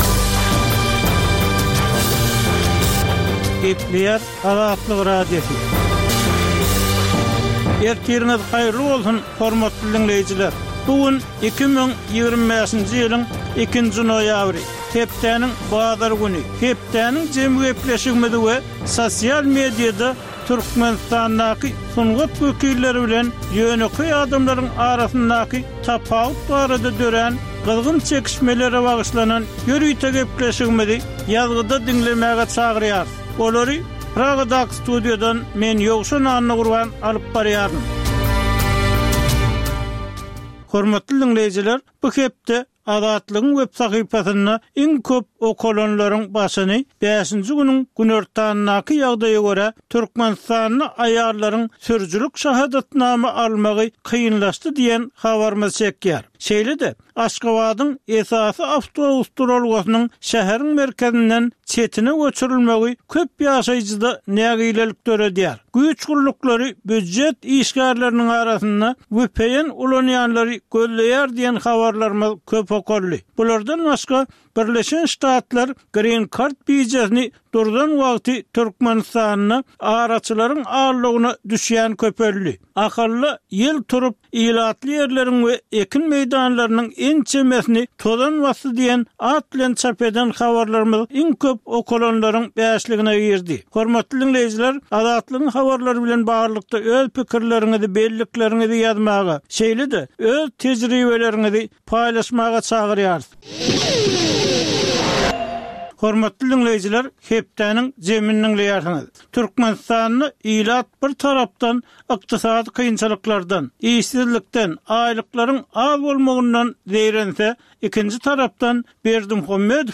Tutuş geçen bir hepdäniň dowamynda Türkmenistanda we halkara arenasynda bolup geçen möhüm wakalara syn. Bu ýörite programmanyň dowamynda hepdäniň möhüm wakalary barada synlar, analizler, söhbetdeşlikler we kommentariýalar berilýär.